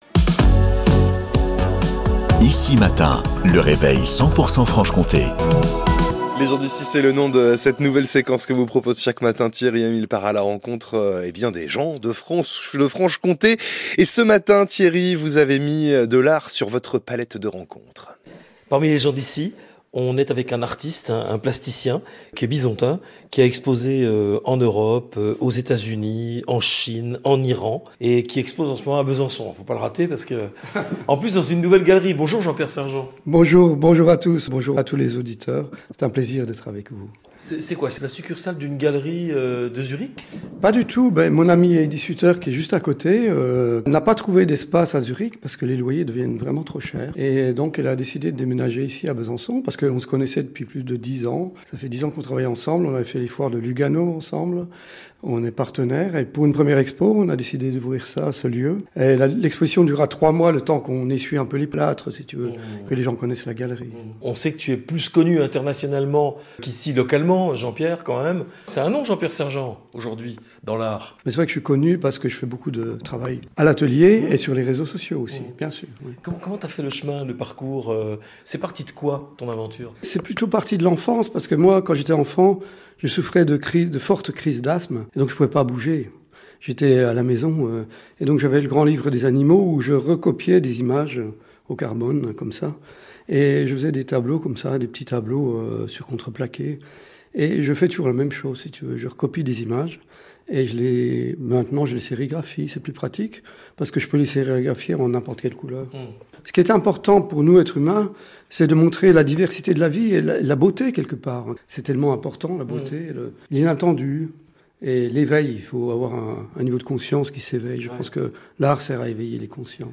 INTERVIEW RADIO